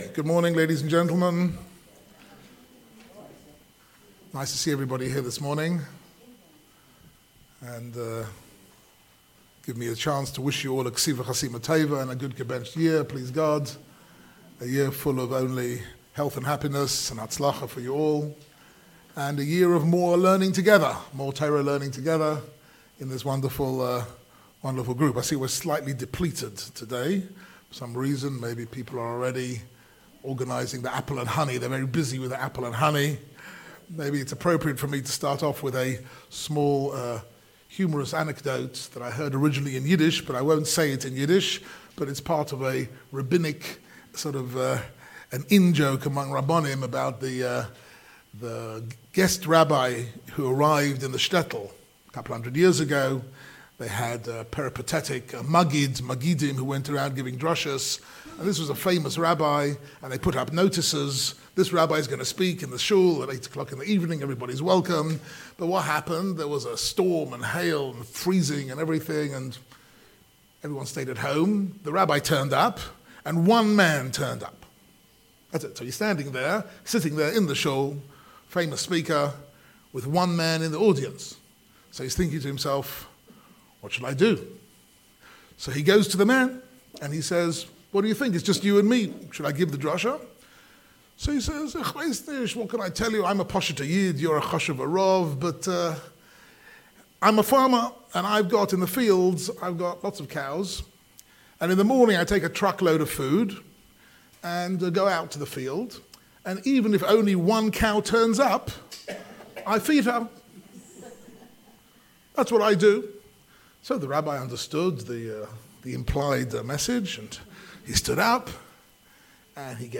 Delivered at the OU Israel Center, September 25, 2019, 25 Elul 5779